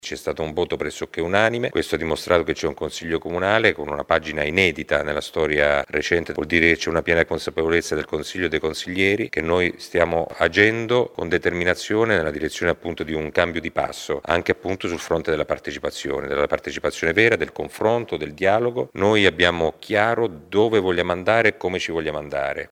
Sentiamo il sindaco Massimo Mezzetti…